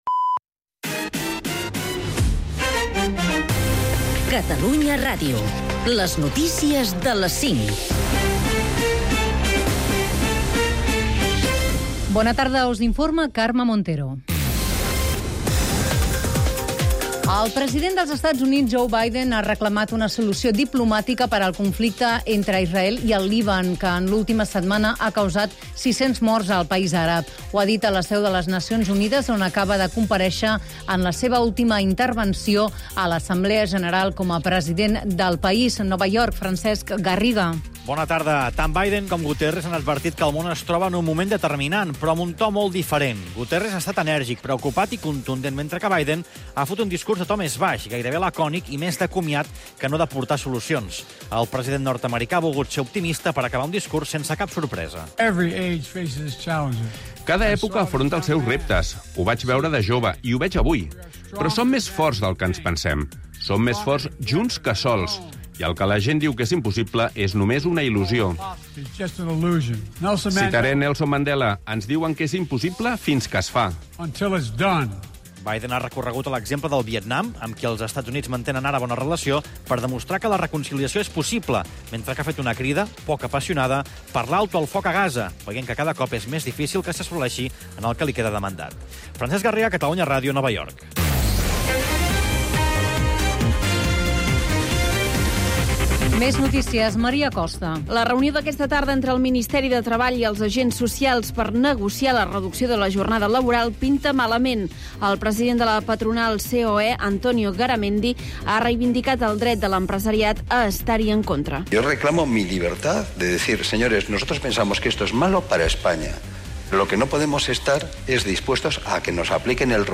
Un programa que, amb un to proper i dists, repassa els temes que interessen, sobretot, al carrer. Una combinaci desacomplexada de temes molt diferents.